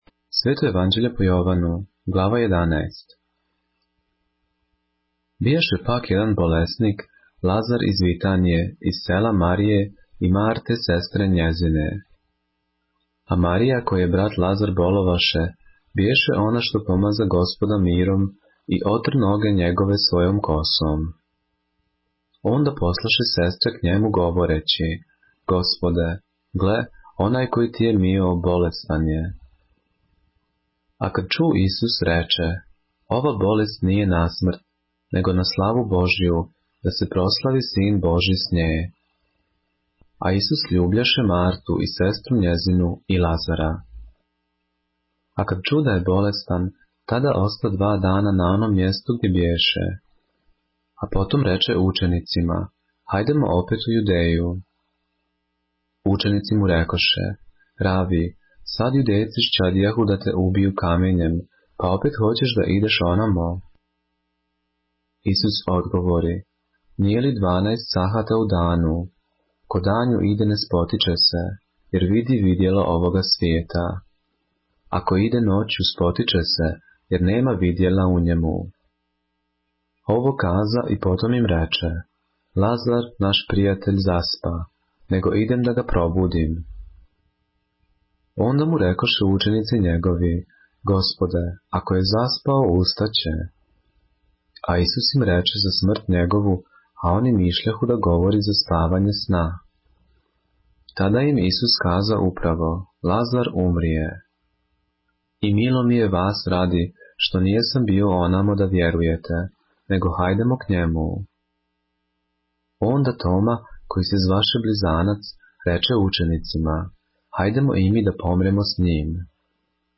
поглавље српске Библије - са аудио нарације - John, chapter 11 of the Holy Bible in the Serbian language